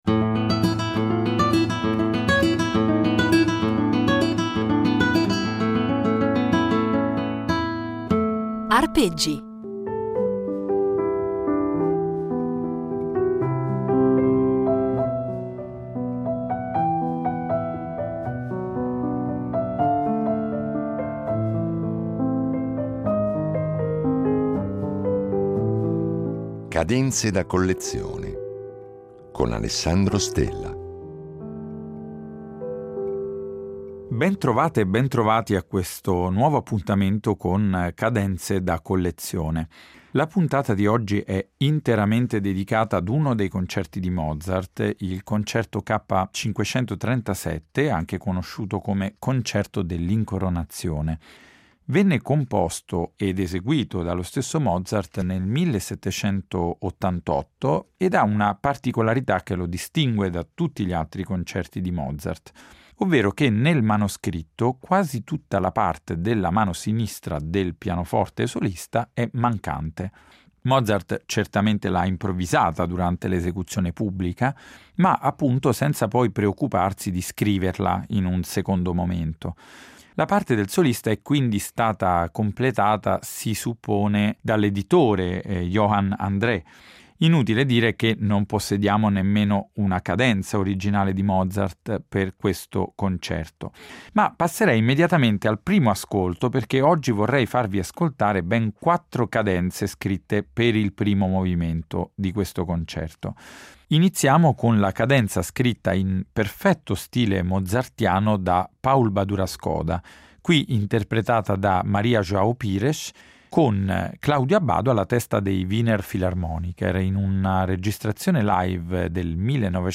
Da quelle scritte dagli autori, a volte in due distinte versioni, a quelle firmate o improvvisate dagli interpreti, dal concerto alla sonata, una bella carrellata di esempi memorabili e significativi commentati per noi da un appassionato specialista della materia.